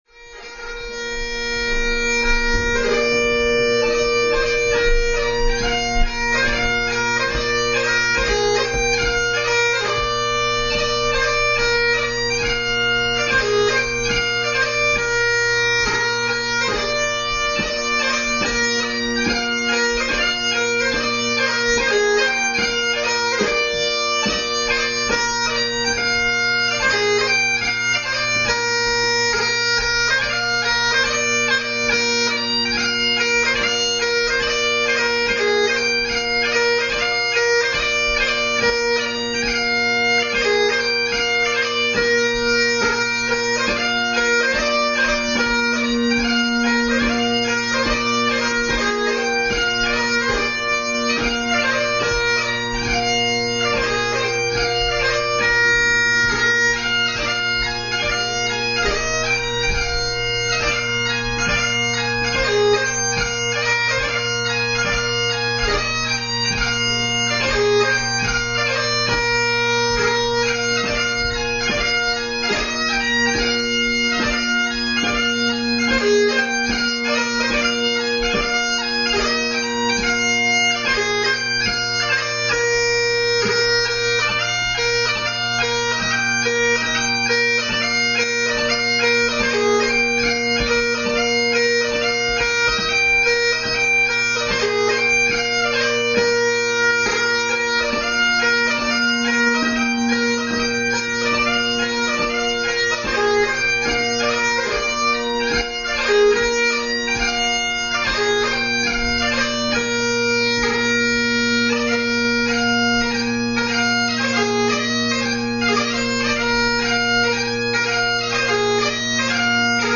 Extracts from 2005 competition